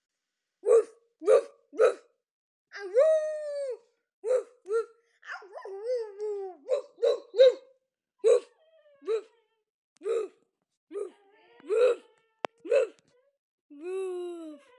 annoying dog sound Sound Button: Unblocked Meme Soundboard
Dog Sounds